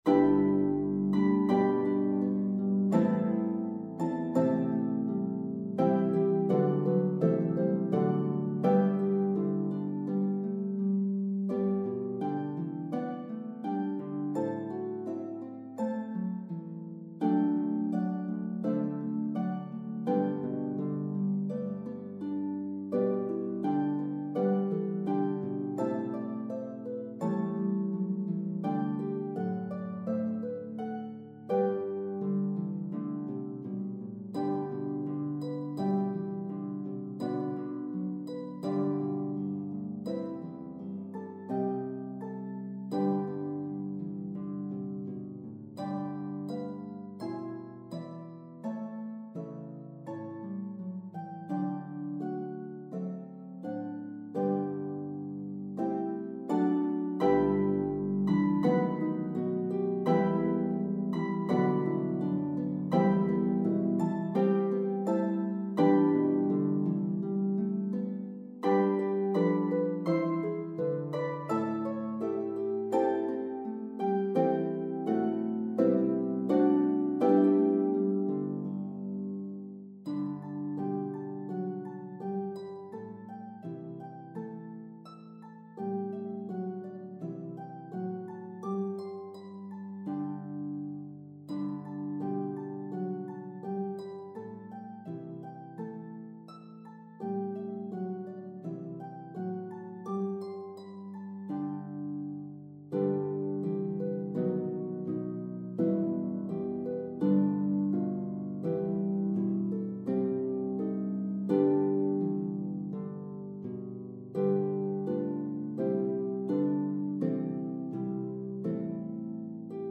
for mixed harp ensemble
Harp 1 is playable on either lever or pedal harp.
The melody is evenly divided between the parts.
Practice tracks
Harp 1